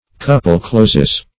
Couple-closes - definition of Couple-closes - synonyms, pronunciation, spelling from Free Dictionary
Couple-closes (k[u^]p"'l*kl[=o]"s[e^]z).